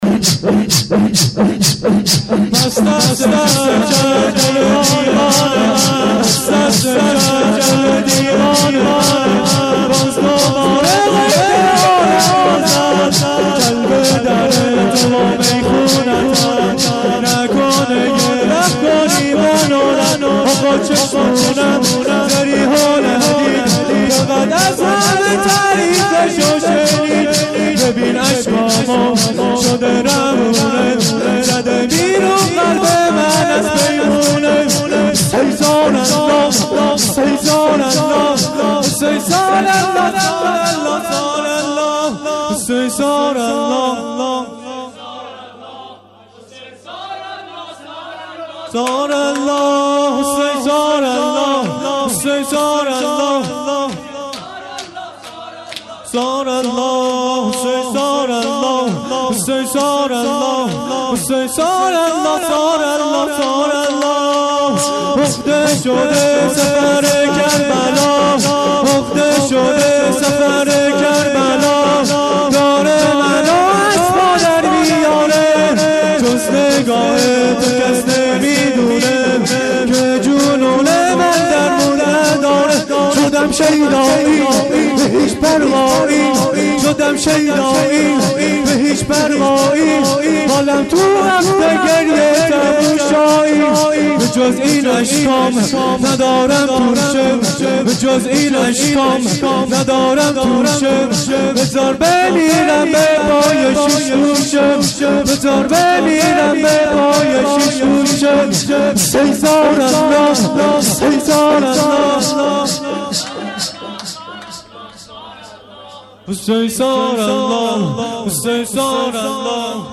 سینه زنی / شور